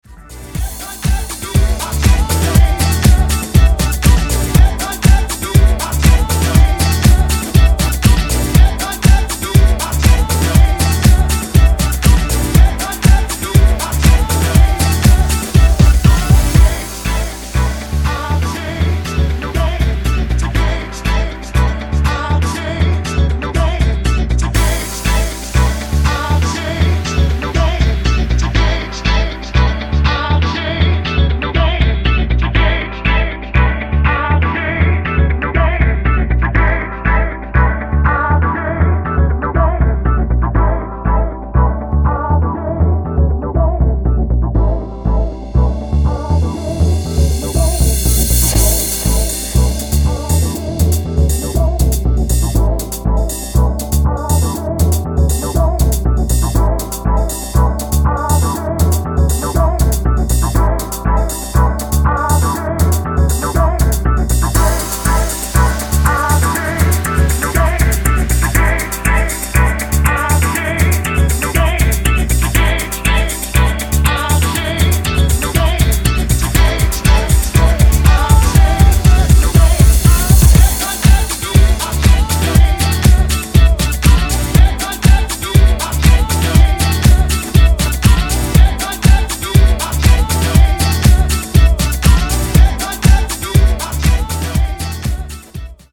リエディット